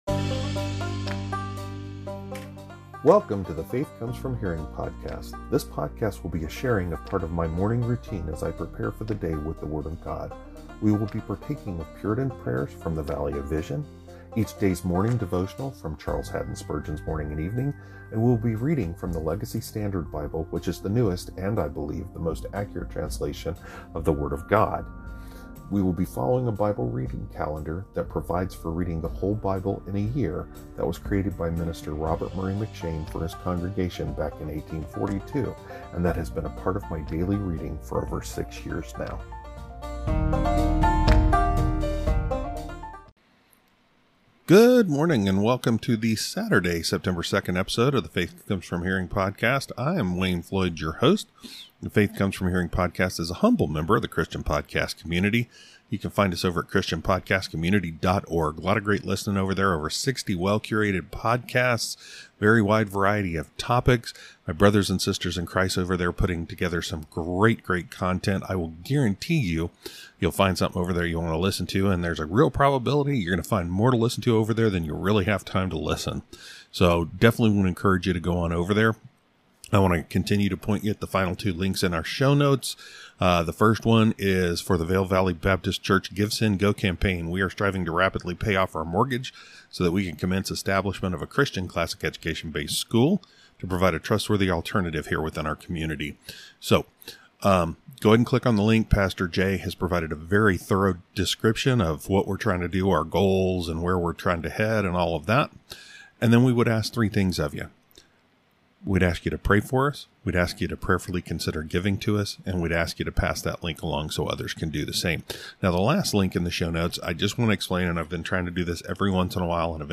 Saturday, September 2, Scripture Readings, Episode 344